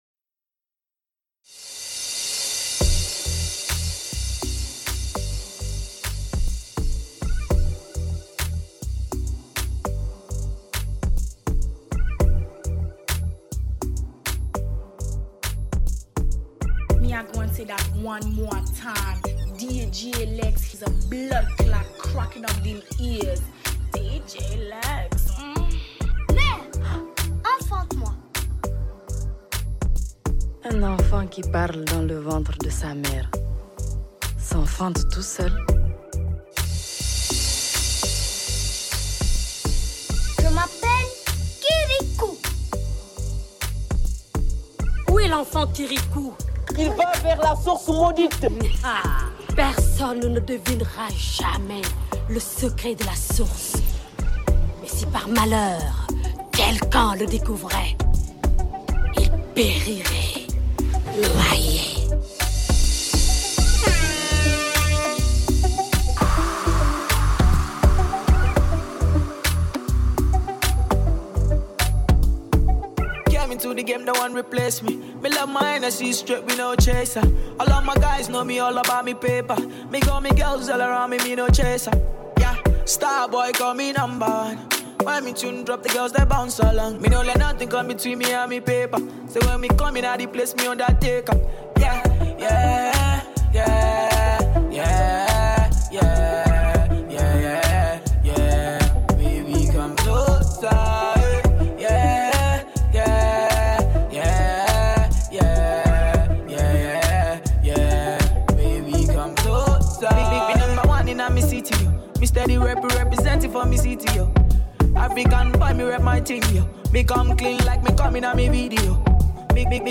Genre: AFRO | SOCA.